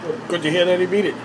MAN IN PIZZA SHOP SAYS “GOOD TO HEAR THAT HE BEAT IT”